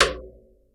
SURDO RIM.wav